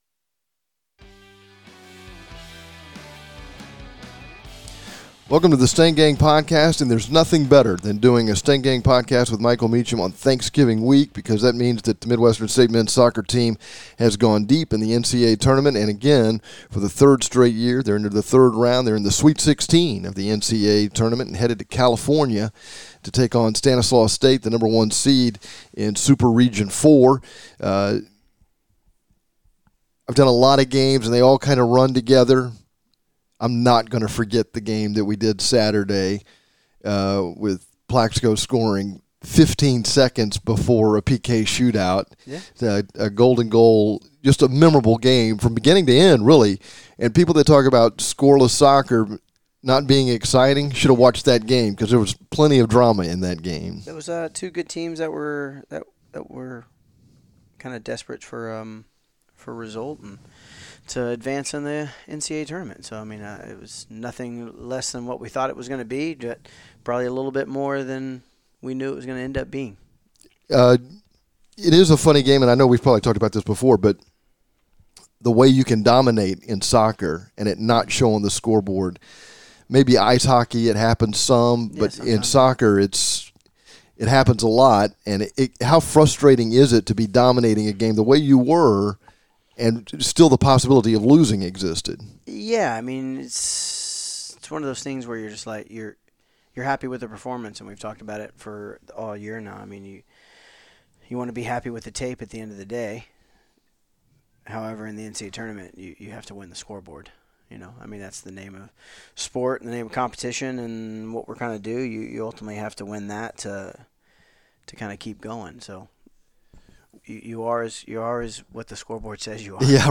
This week's Stang Gang Podcast features complete coverage of Midwestern State Men's Soccer and an interview